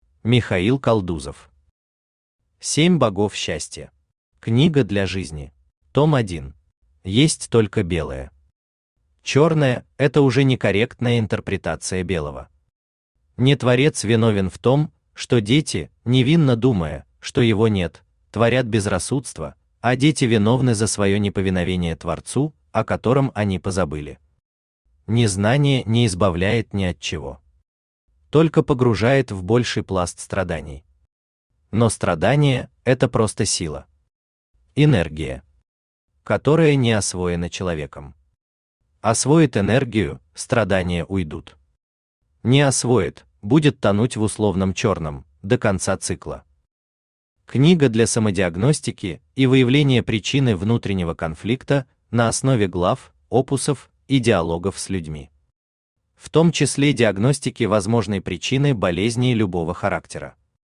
Аудиокнига 7 богов счастья. Книга для жизни | Библиотека аудиокниг